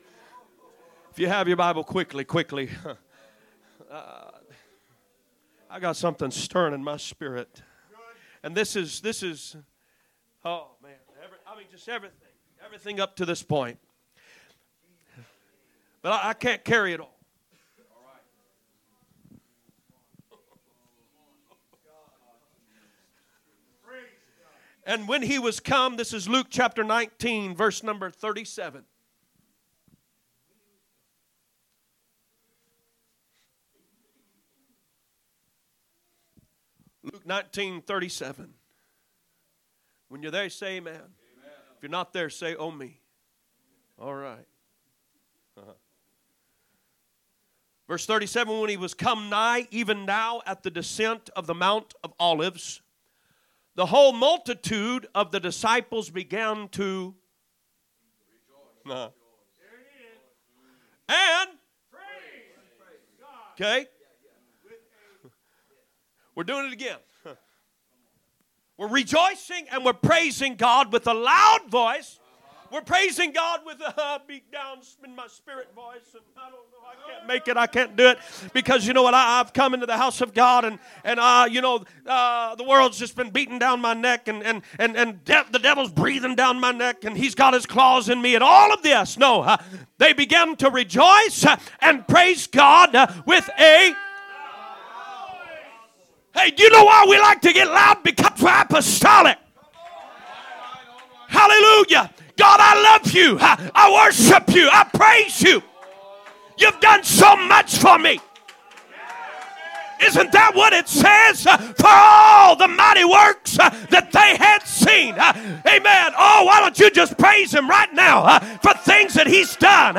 Monday Message - Revival